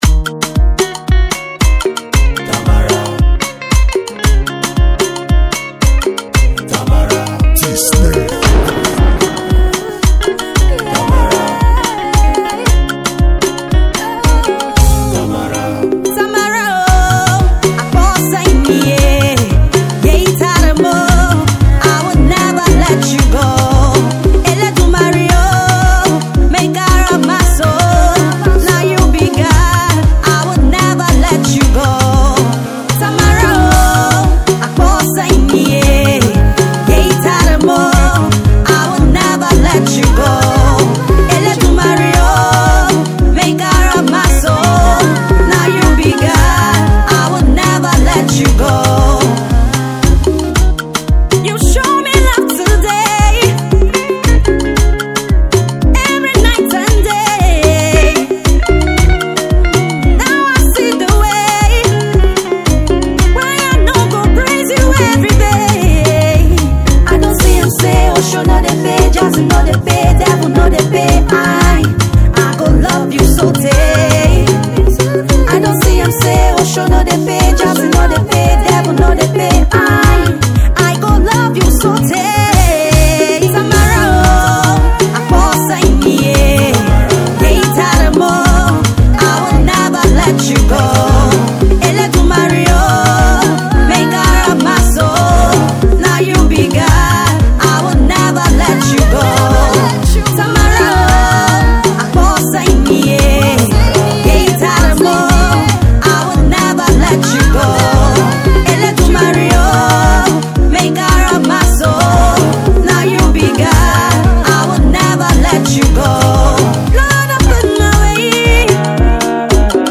praise song
gospel music